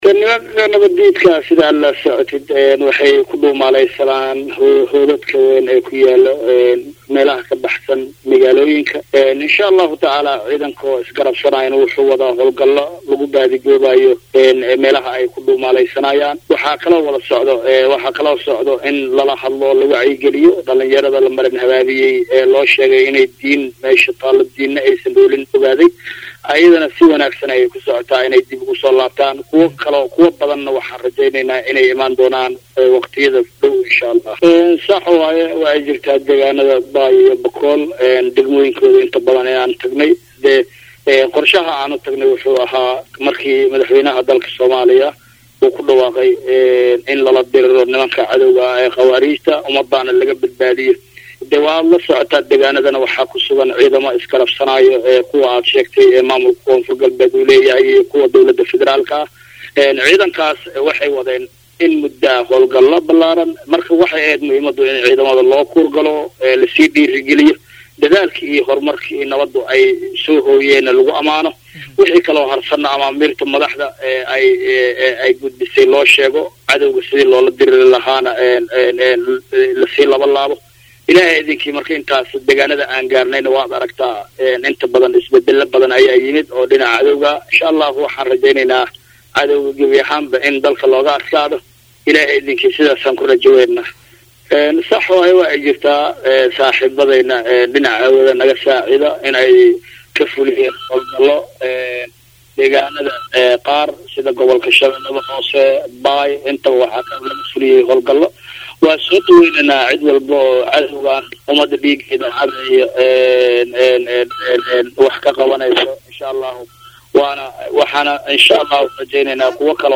Halkaan ka DHageyso Codka Wasiirka
Wasiirka-Amniga-Koonfur-galbeed-Soomaaliya-Xasan-Xuseen-Maxamed-Eelaay.MP3-mmmmmmmmmmmmmmm.mp3